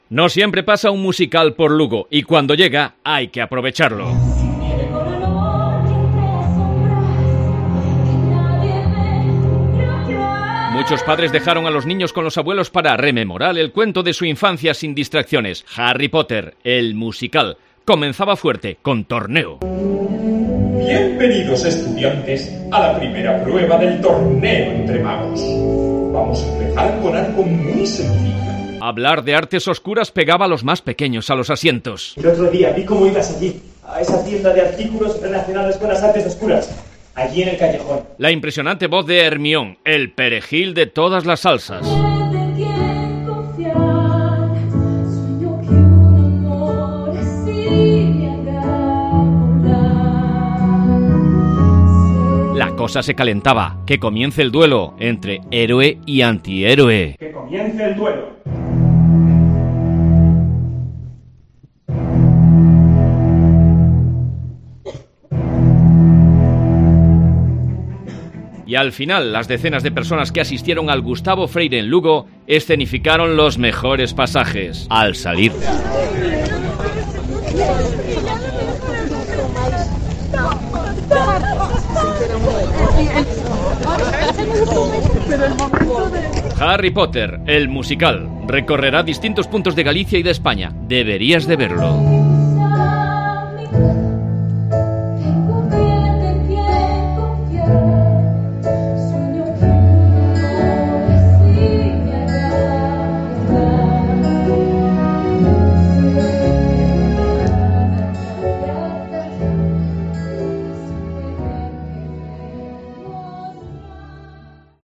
La representación tuvo lugar en el auditorio de Lugo